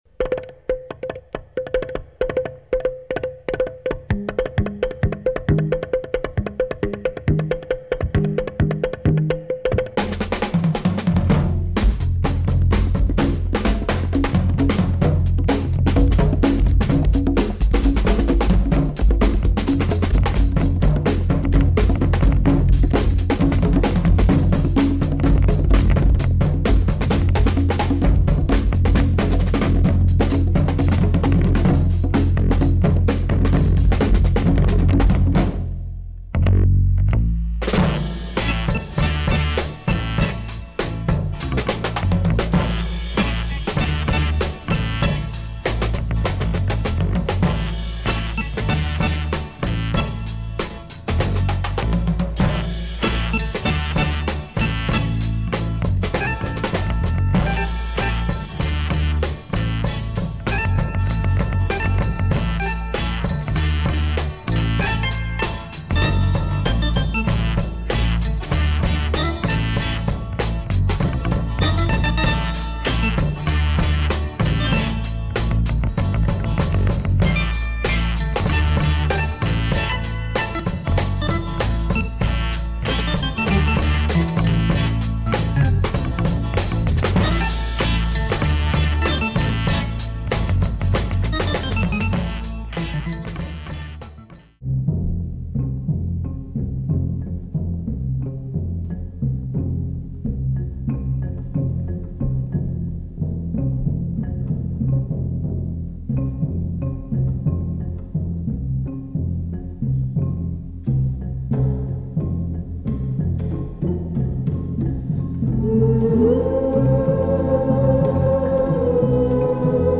delightful female chant
hard drum beat and groove
light exotic
mysterious sounds